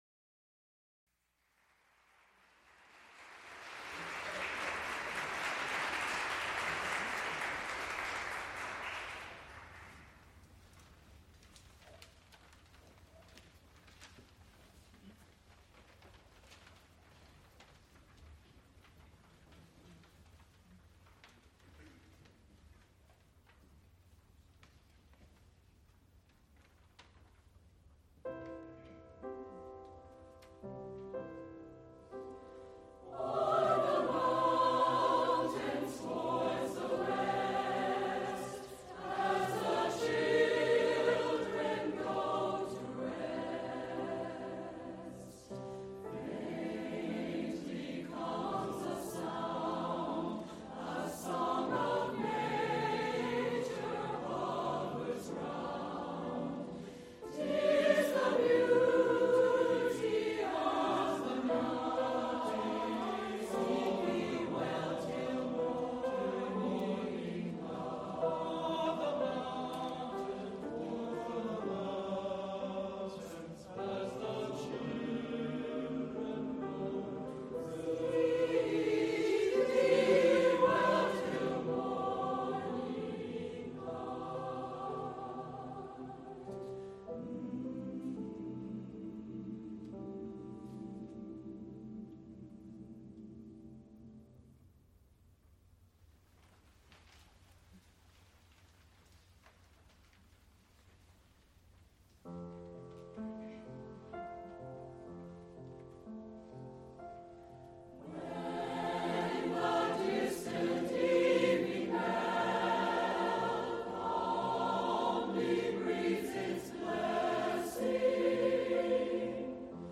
piano
Recorded live December 3, 1978, Heinz Chapel, University of Pittsburgh.
sound recording-musical
musical performances
Choruses, Secular (Mixed voices) with piano